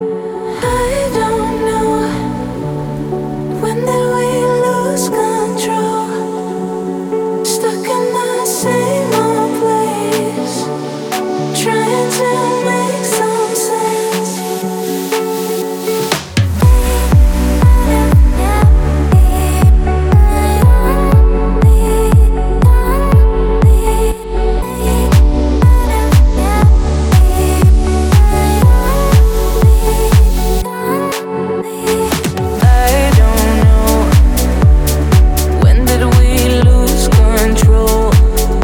в хорошем качестве